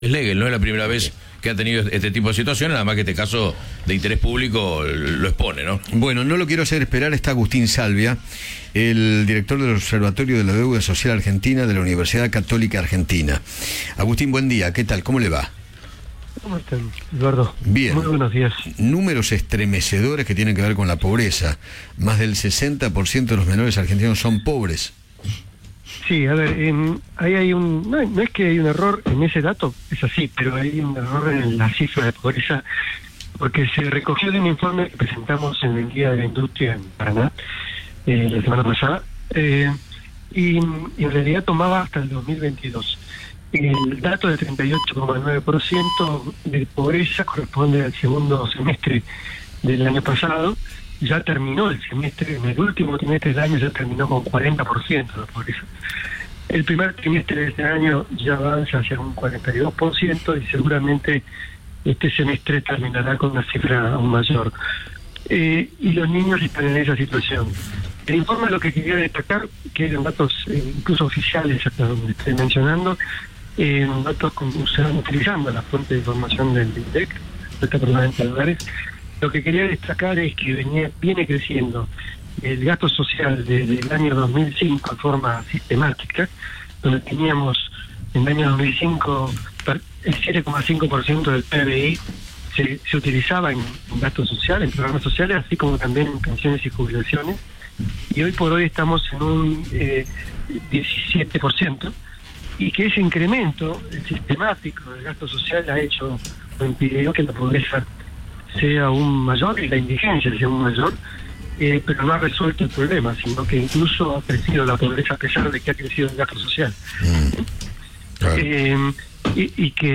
conversó con Eduardo Feinmann sobre el informe que publicó la Universidad acerca del aumento de la pobreza en el país.